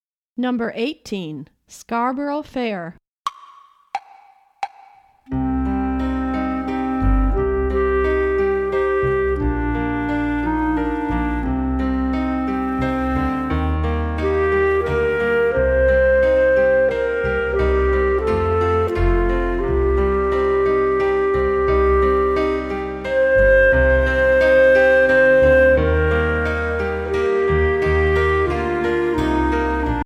Voicing: Euphonium Treble Clef